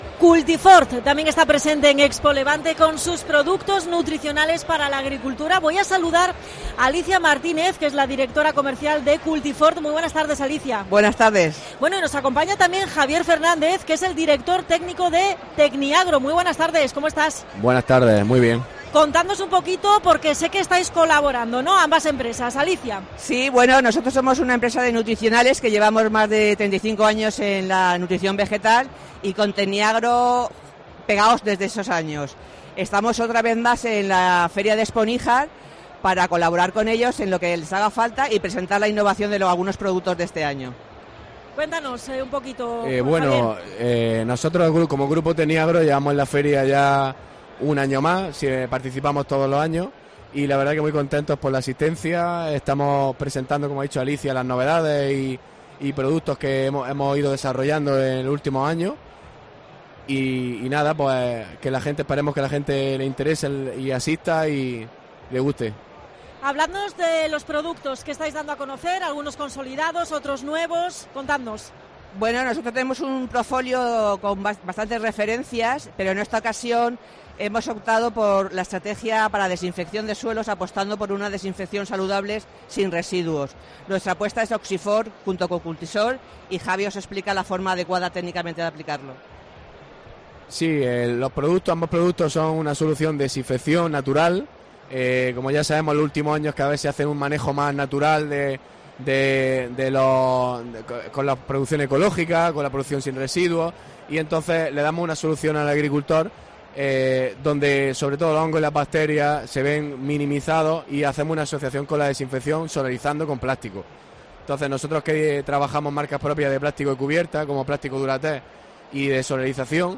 AUDIO: Especial COPE Almería desde ExpoLevante en Níjar. Entrevista a Cultifort.